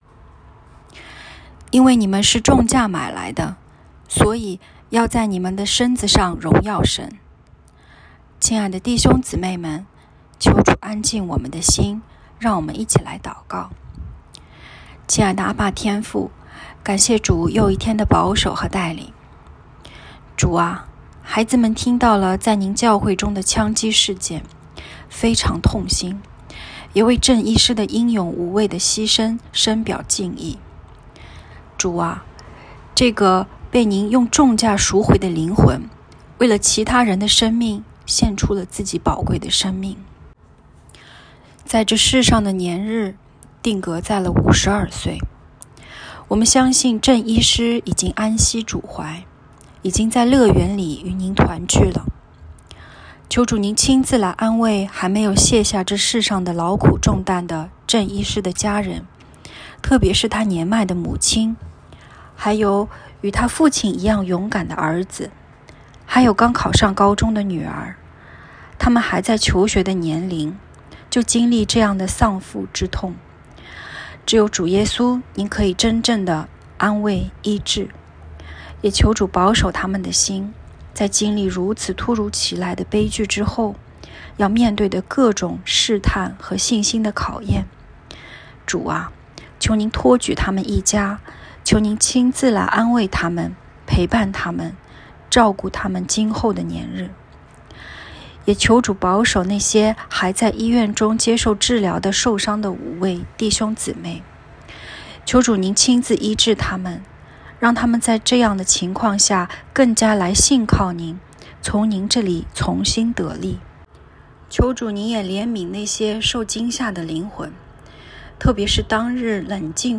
✨晚祷时间✨5月20日（周五）